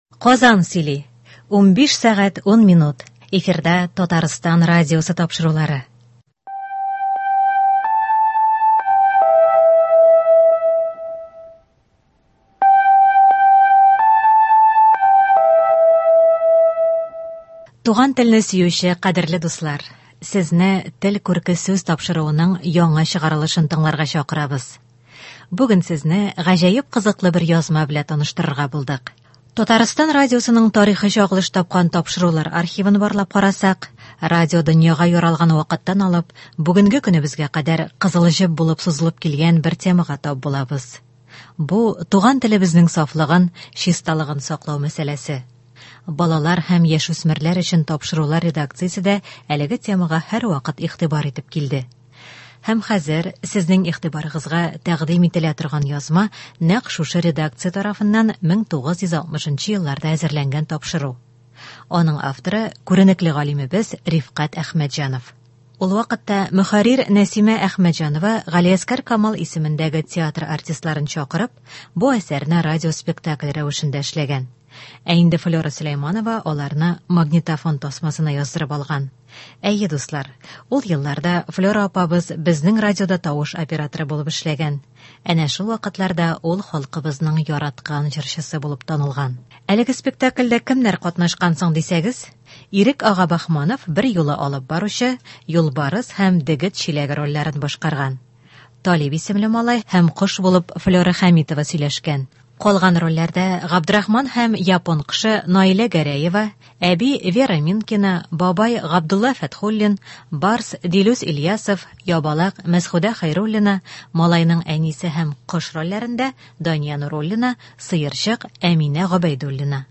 Һәм хәзер сезнең игътибарыгызга тәкъдим ителә торган язма — нәкъ шушы редакция тарафыннан 1960 елларда әзерләнгән тапшыру.
Галиәсгар Камал исемендәге театр артистларын чакырып, бу әсәрне радиоспектакль рәвешендә эшләгән.
аларны магнитофон тасмасына яздырып алган.